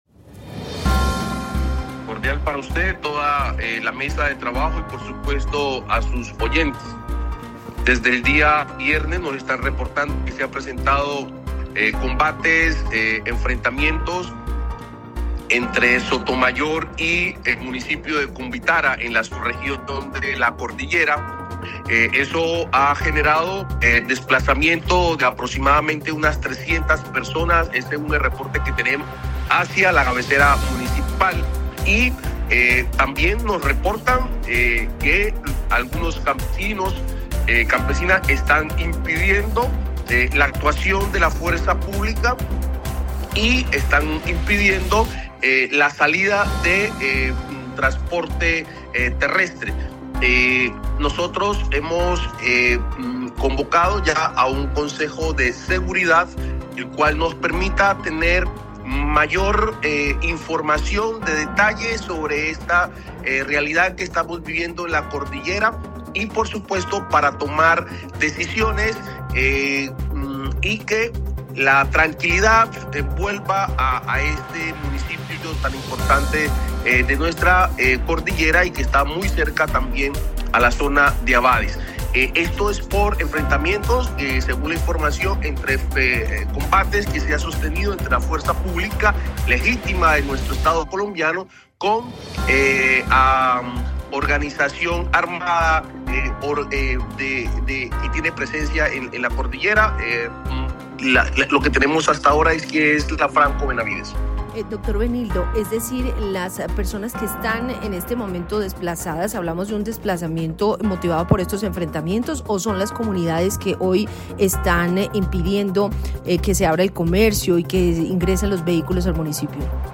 Benildo Estupiñan-Secretario de Gobierno